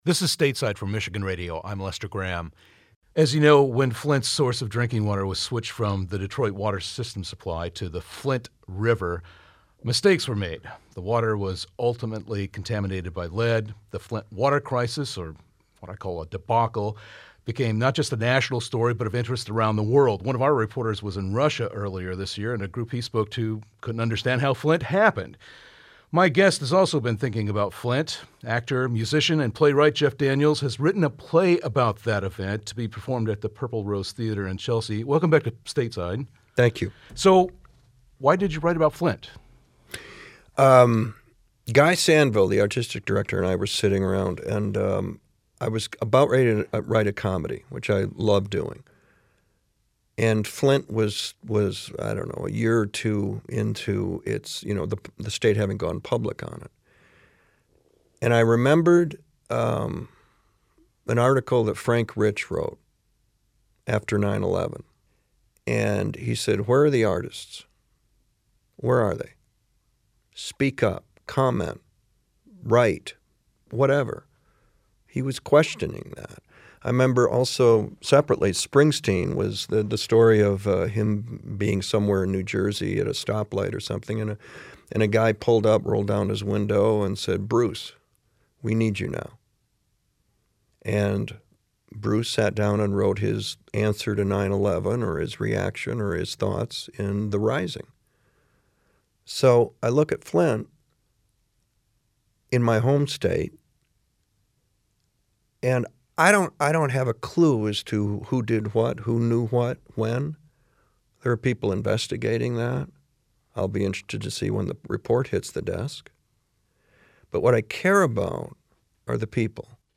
Stateside's conversation with actor, playwright and musician Jeff Daniels.